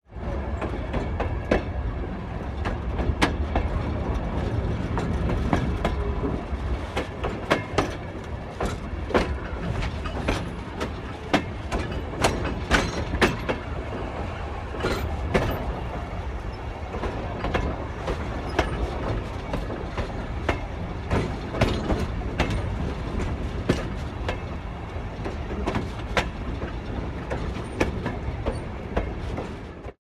in_sttrain_clacking_01_hpx
Steam train chugs along with tracks squeaking. Engine, Steam Train Locomotive, Tracks Train, Steam